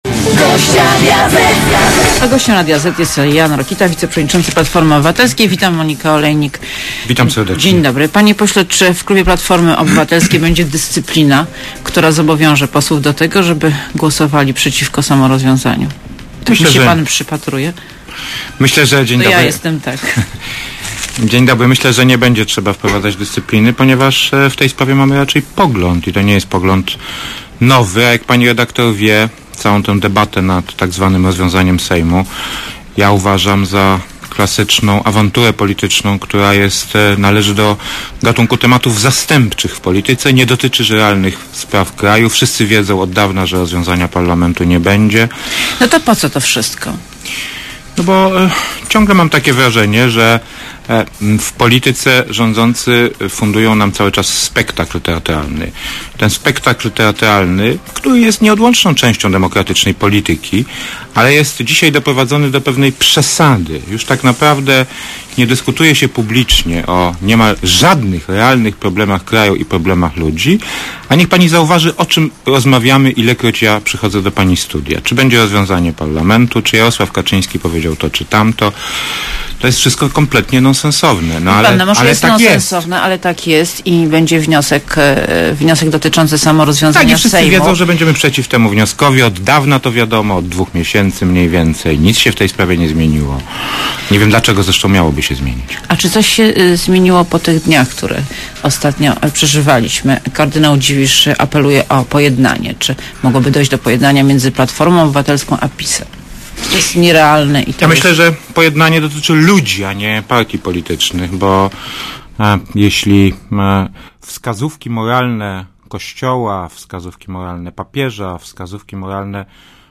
Gościem Radia ZET Jan Rokita, wiceprzewodniczący Platformy Obywatelskiej. Wita Monika Olejnik, dzień dobry.
Posłuchaj wywiadu Gościem Radia ZET Jan Rokita, wiceprzewodniczący Platformy Obywatelskiej.